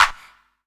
Index of /90_sSampleCDs/Best Service Dance Mega Drums/CLAPS HOU 1B